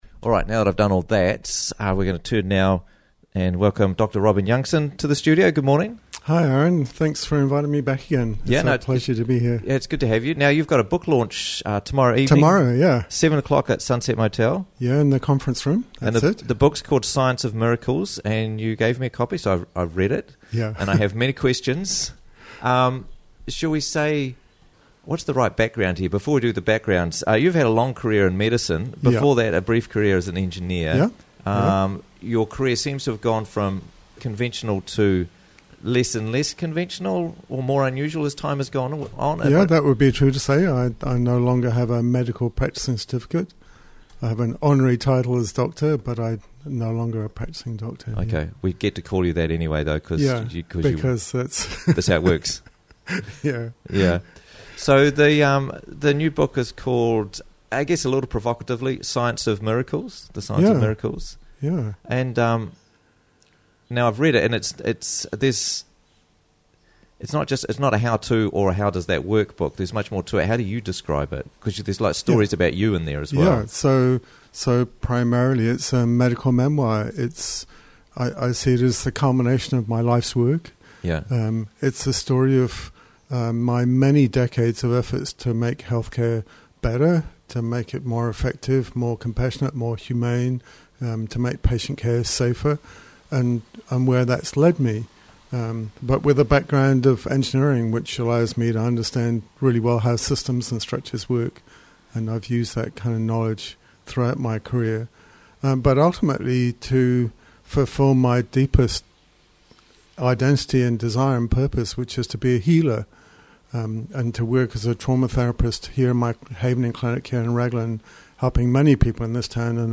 The Science of Miracles Book Launch - Interviews from the Raglan Morning Show